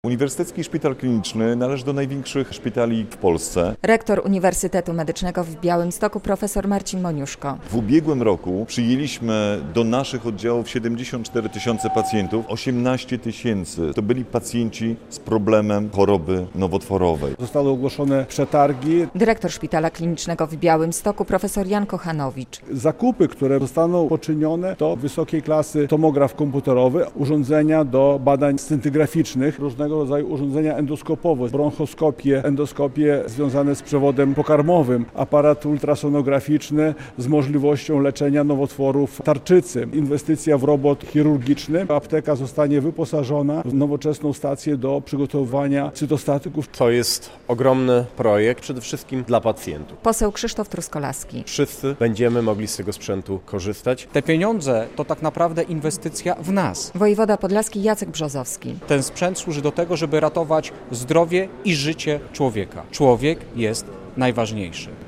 Nowy sprzęt trafi do białostockiego szpitala klinicznego - relacja